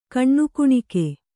♪ kaṇṇukuṇike